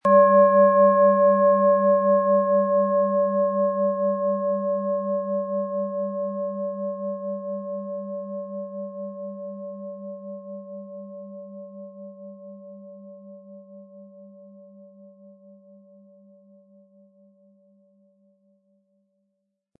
Tibetische Universal-Klangschale, Ø 16,7 cm, 500-600 Gramm, mit Klöppel
Ihre -Klangschale wird mit dem beiliegenden Schlägel schön erklingen.